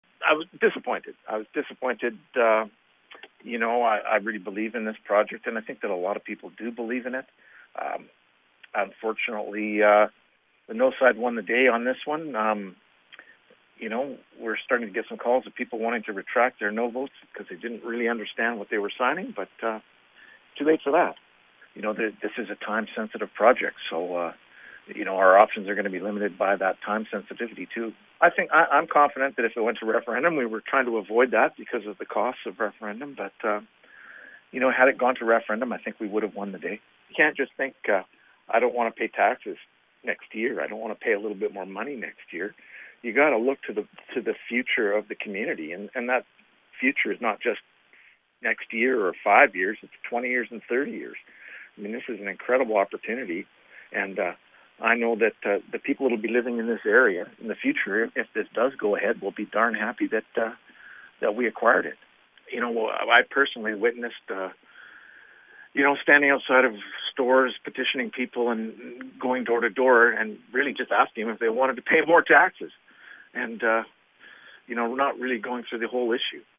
Lake Country Councillor Blair Ireland offers initial reaction just ahead of a crucial meeting of Lake Country's local politicians that began at 5:00 this afternoon.